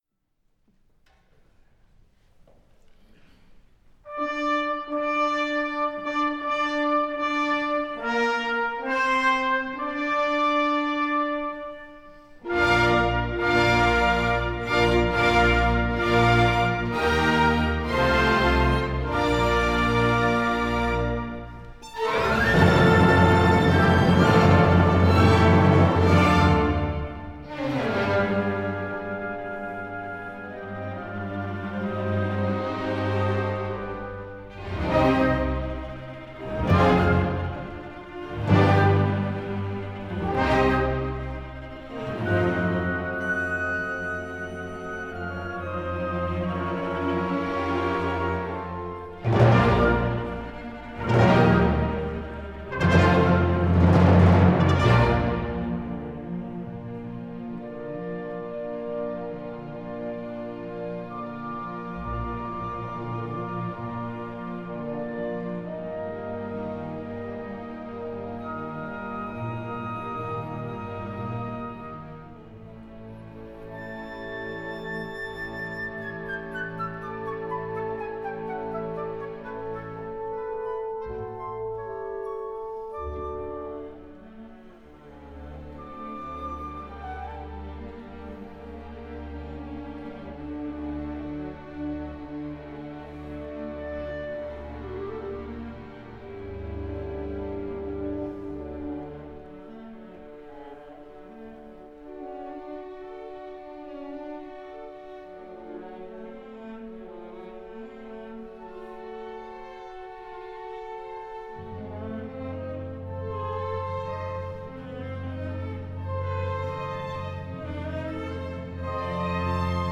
Robert Schumann: Sinfonía nº 1 en si bemol mayor Op. 38 "Primavera"